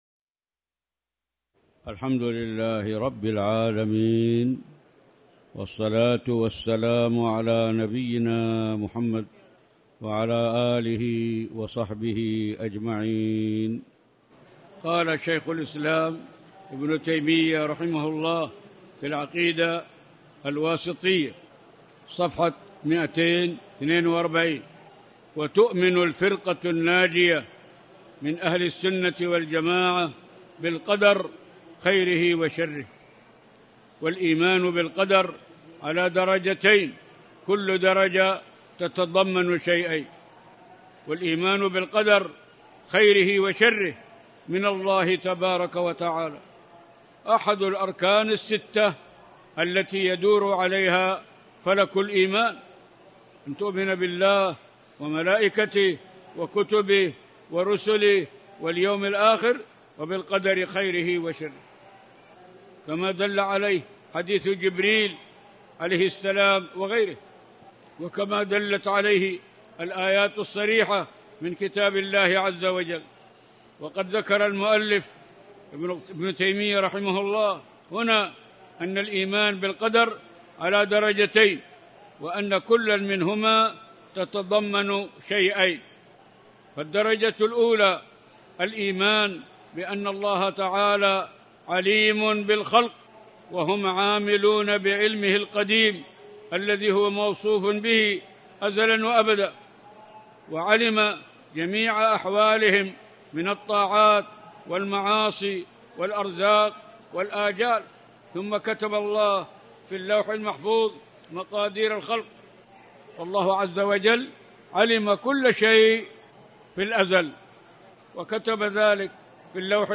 تاريخ النشر ٧ ذو القعدة ١٤٣٨ هـ المكان: المسجد الحرام الشيخ